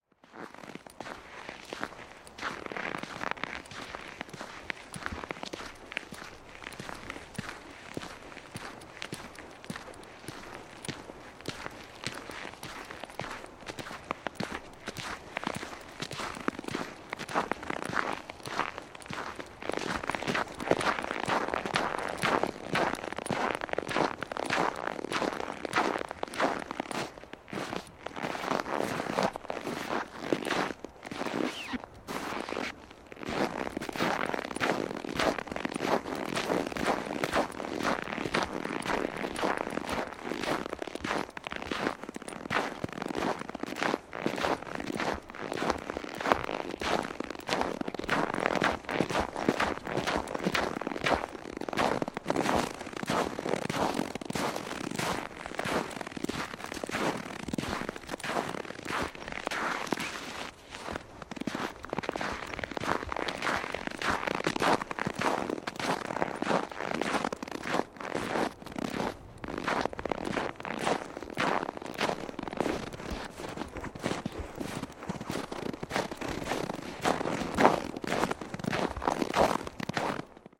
脚步声" 脚步声 雪地上的清脆声 近距离的金属碰撞声
描述：在冬季的一天，关闭一个人在雪地里的脚步声。雪是干的，所以声音很脆。衣服/拉链可以听到无比的声音。使用Neumann KMR 81i录制，声音设备744 T.
Tag: 关闭 无比 金属 脆脆的 脚步